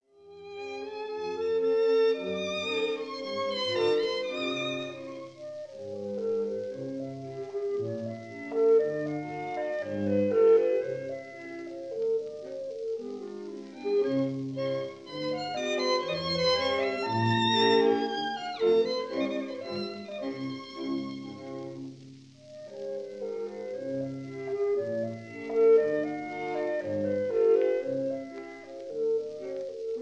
clarinet
bassoon
horn
violins
viola
cello
double-bass
Recorded in Société suisse de radiodiffusion
studio, Geneva in July 1948